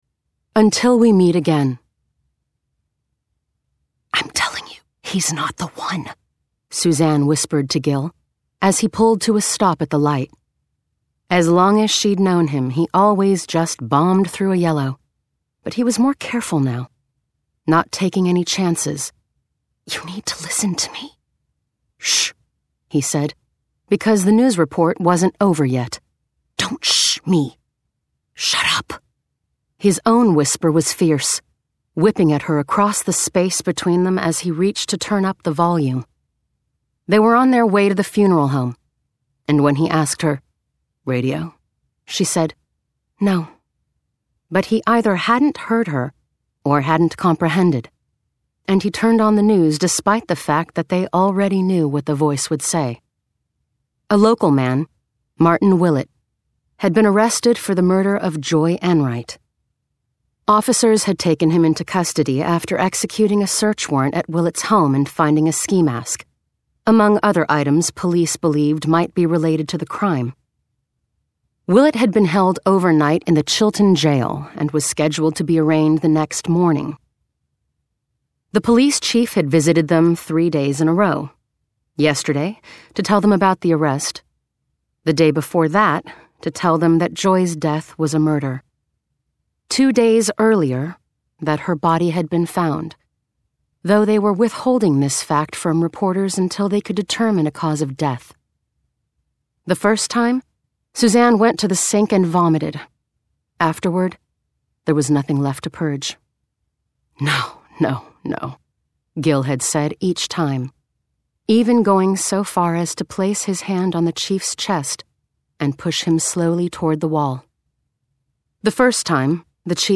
Audiobooks
Her voice range spans between teens, 20s – 30s and middle age, and her accents most known for are American English, British English, Australian/ South African English, American South, French, Italian, New York, South American, Spanish.